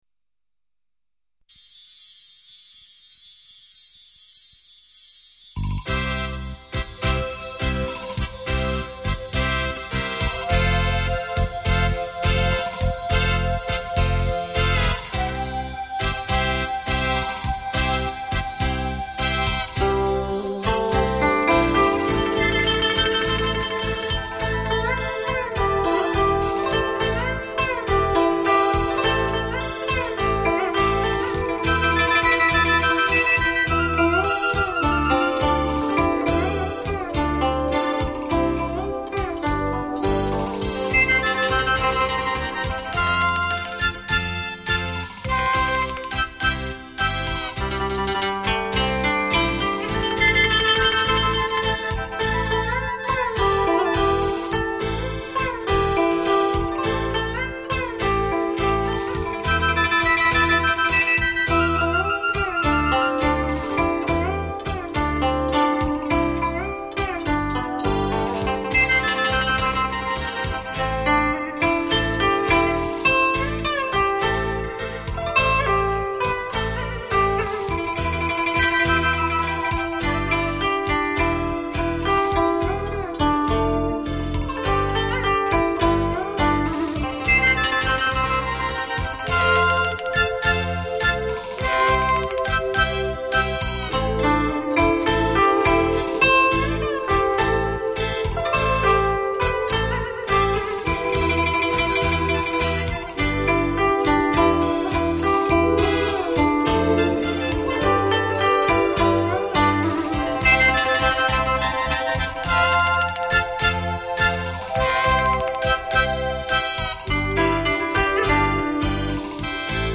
样本格式    : 44.100 Hz;16 Bit;立体声